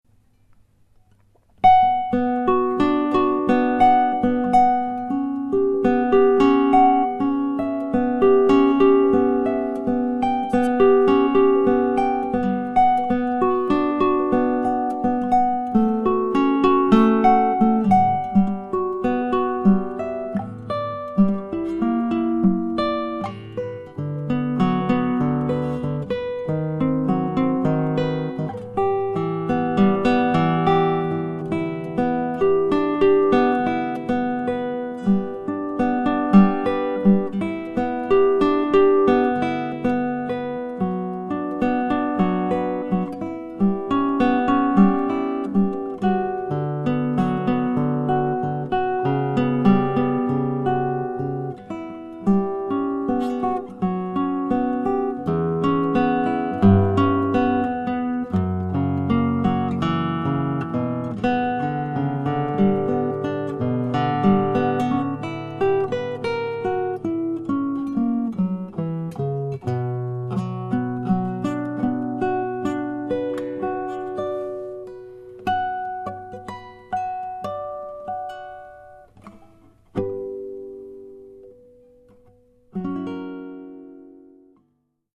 J'ai acheté un Zoom H4.
L'enregistrement est brut sans aucun effet (à part les pains qui sont des effets naturels ):
C'est une sept cordes....j'avais loupé ce detail!!
C'est fou, plus de trente années ont passé,j'ai eu je crois toutes les gammes ou genres de guitares, et je reviens -comme ado- à ce son nylon qui est pour moi le plus beau d'entres tous.Ce fut mon "premier amour"!!